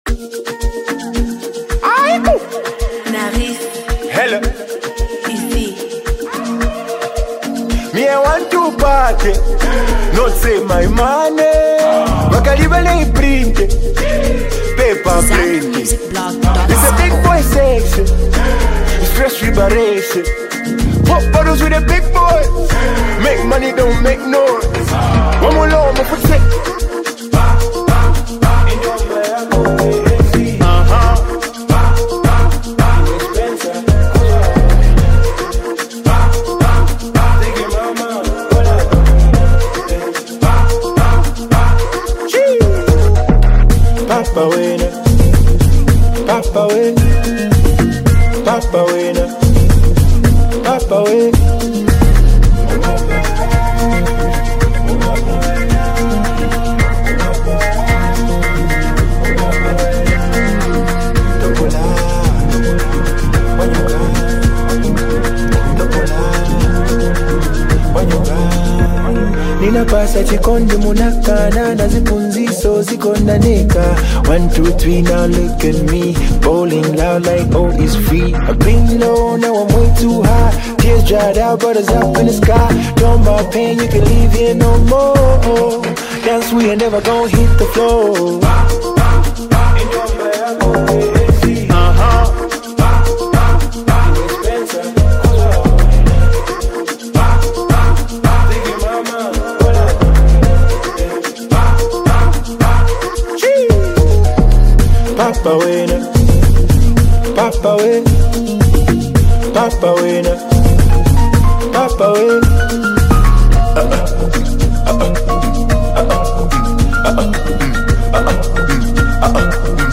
dance-ready track
With its infectious beat and catchy hook